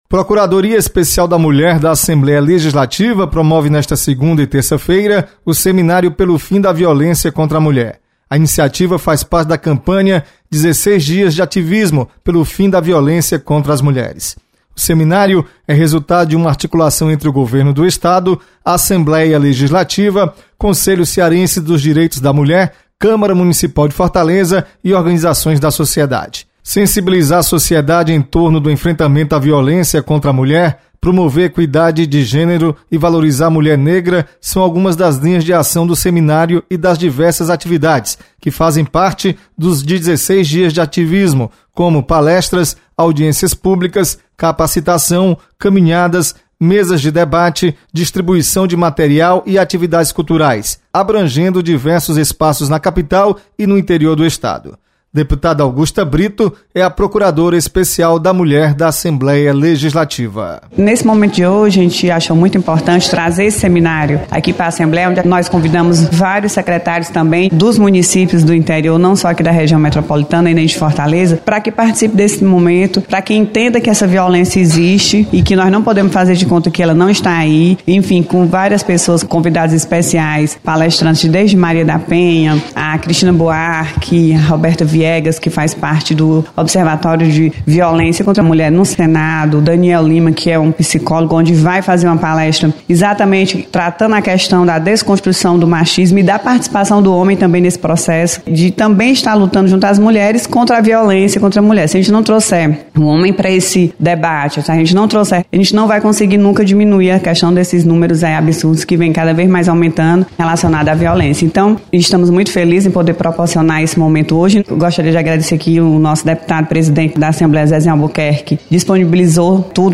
Você está aqui: Início Comunicação Rádio FM Assembleia Notícias Procuradoria